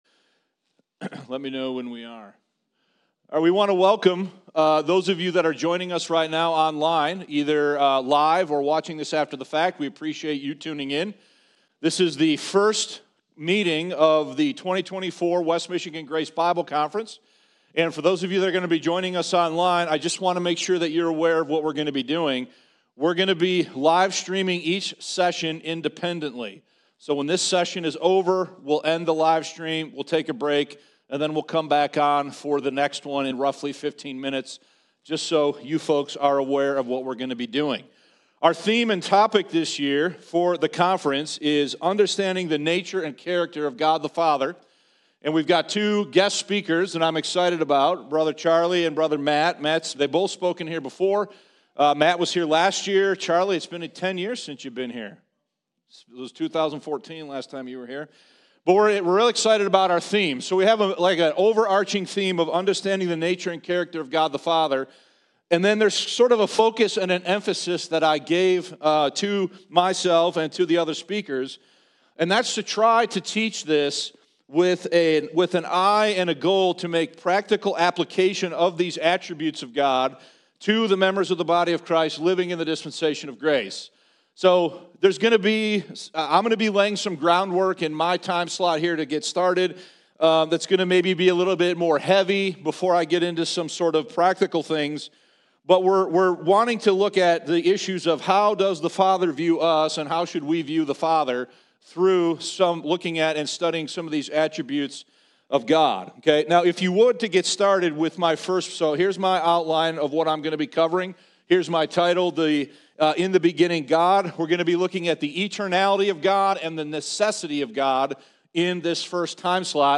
2024 West Michigan Grace Bible Conference } Understanding The Nature & Character of God The Father